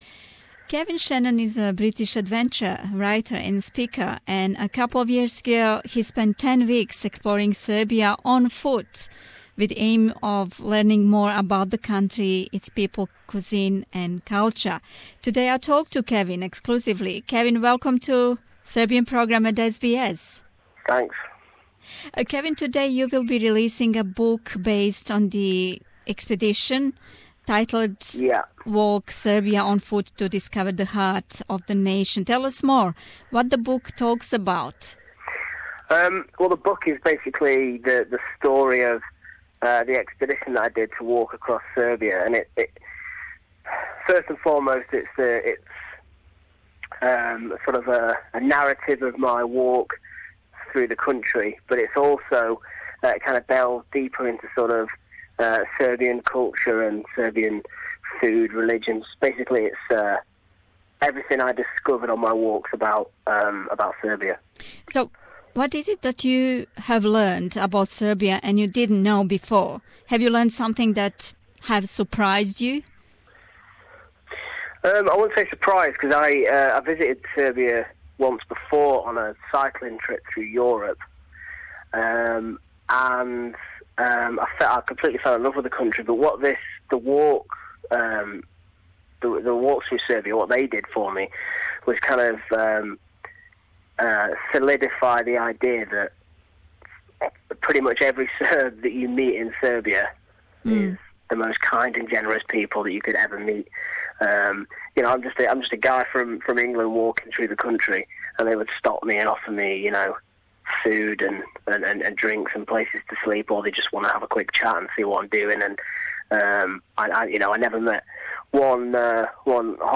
(интервју на енглеском)